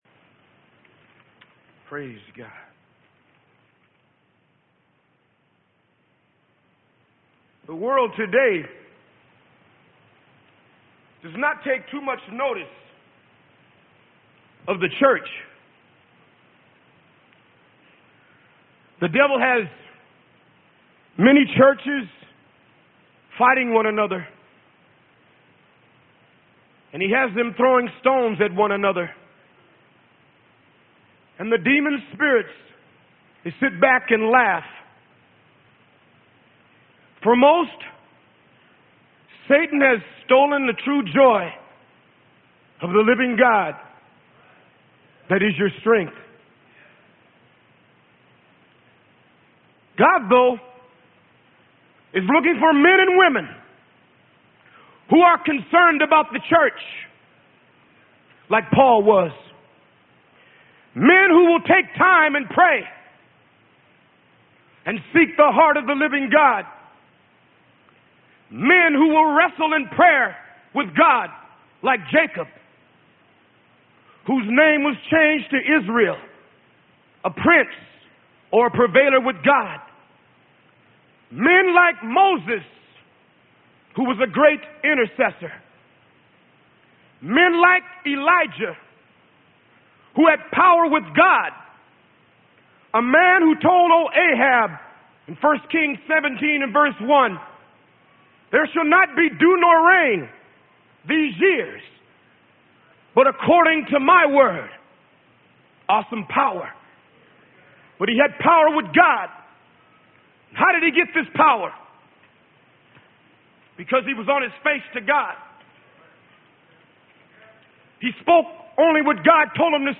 Sermon: Keep Not Silent - Freely Given Online Library